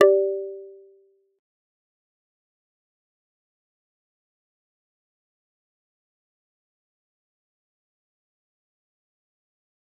G_Kalimba-G4-mf.wav